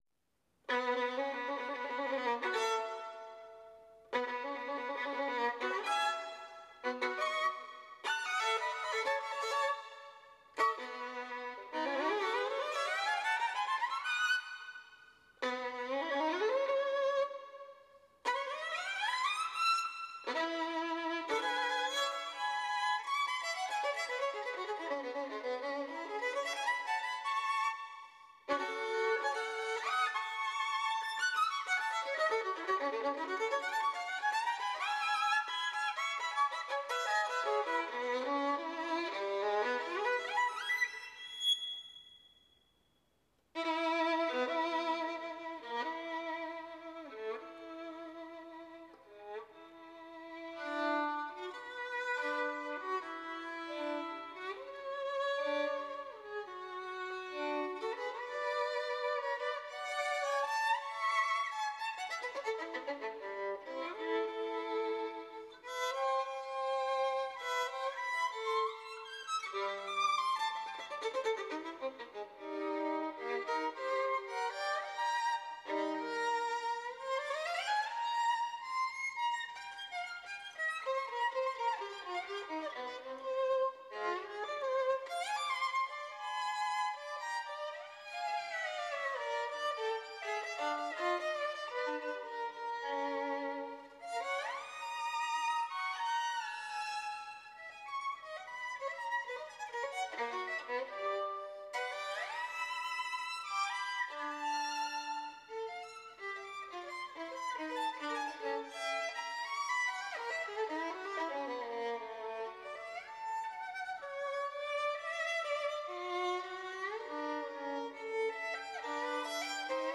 Sonata for solo violin No. 6 , Op. 27, Eugene Ysaye